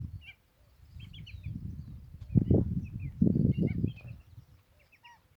Maçarico-de-perna-amarela (Tringa flavipes)
Nome em Inglês: Lesser Yellowlegs
Província / Departamento: Entre Ríos
Condição: Selvagem
Certeza: Observado, Gravado Vocal